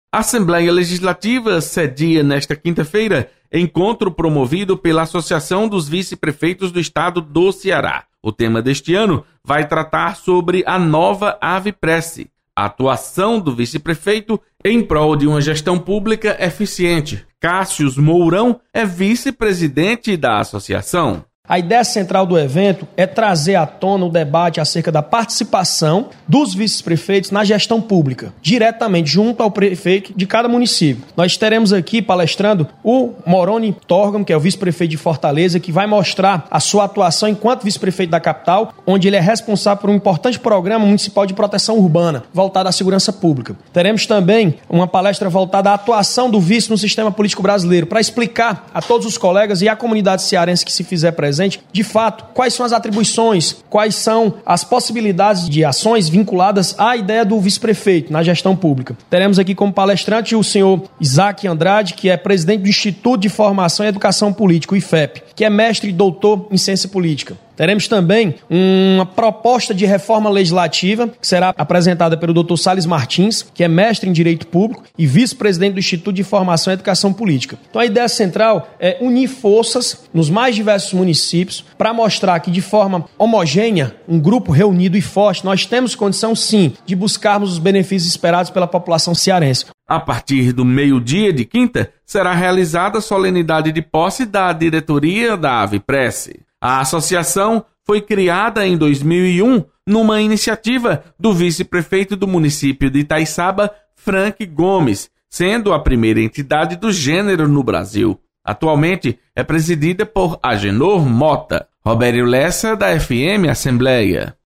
Assembleia sedia encontro da Aviprece. Repórter